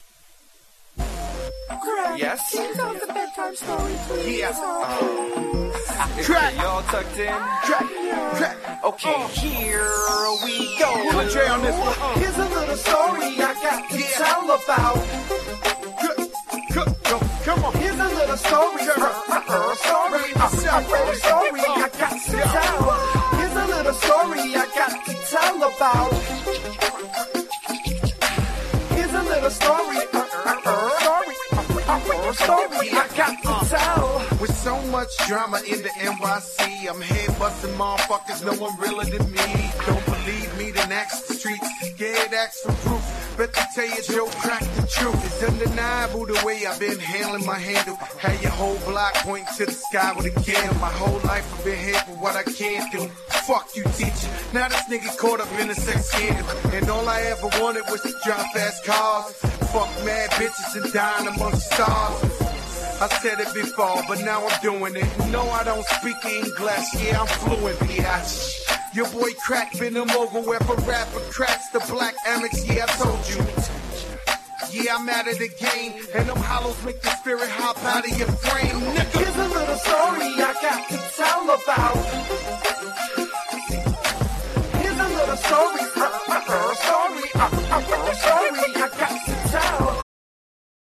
EXPLICIT